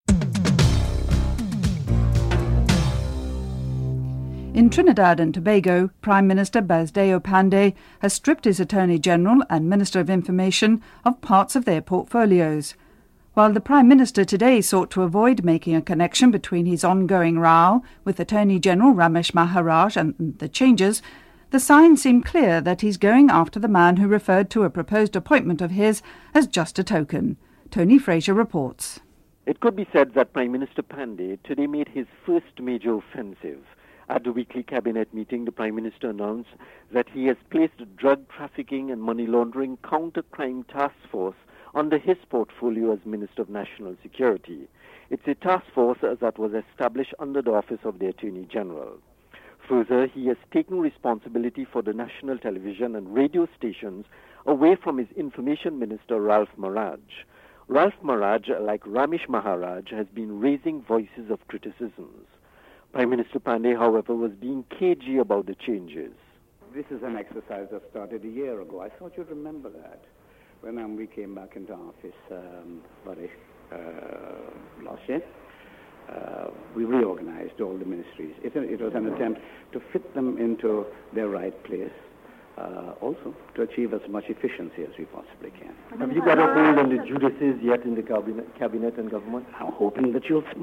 dc.creatorThe British Broadcasting Corporationen_US
Prime Minister Basdeo Panday and Attorney General Ramesh Maharaj are interviewed.
dc.typeRecording, oralen_US